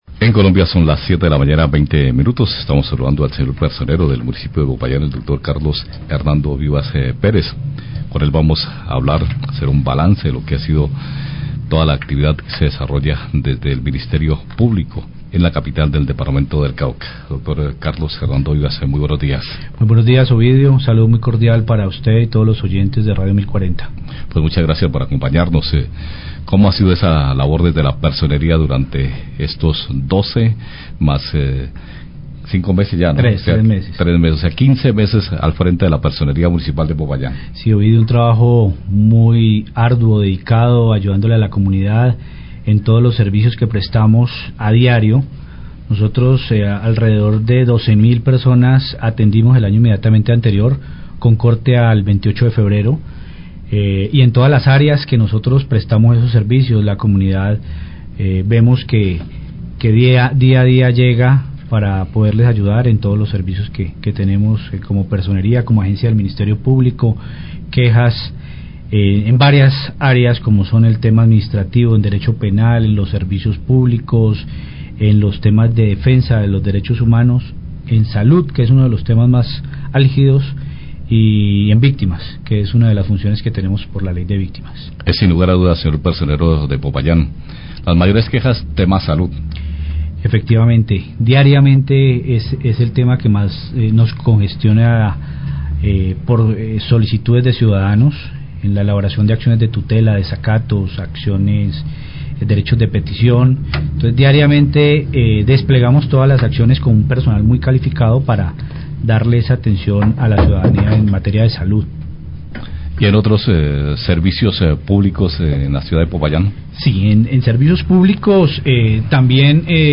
ENTREVISTA CON PERSONERO DE POPAYÁN 01
Radio